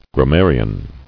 [gram·mar·ian]